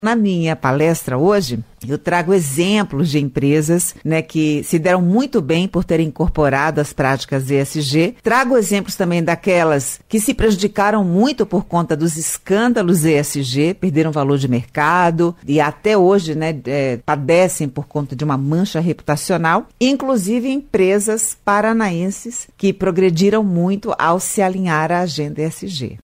Em entrevista à CBN Cascavel, nesta terça-feira (12), ela falou sobre a necessidade de se debater o tema.